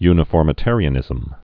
(ynə-fôrmĭ-târē-ə-nĭzəm)